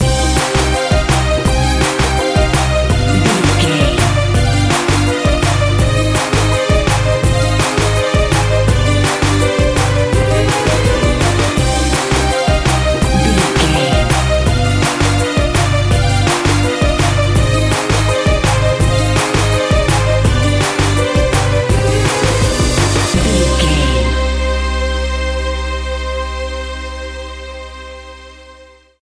Aeolian/Minor
Fast
World Music
percussion